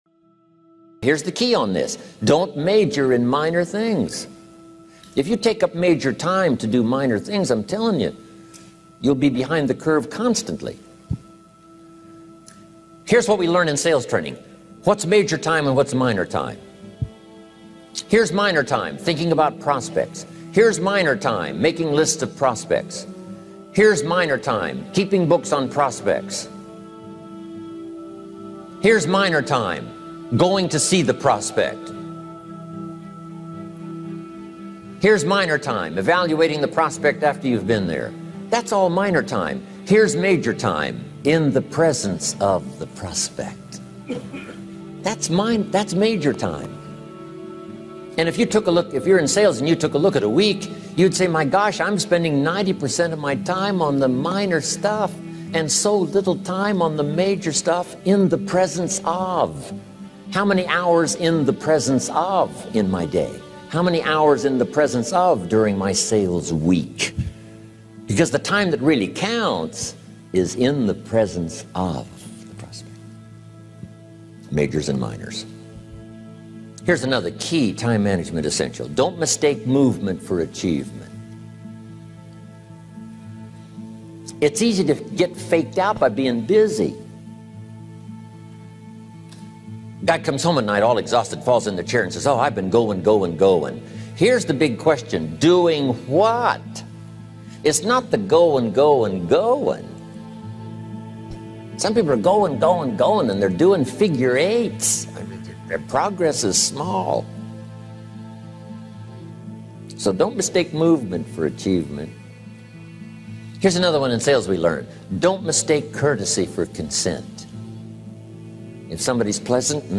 Focus on One Thing – Jim Rohn Speech - Dynamic Daydreaming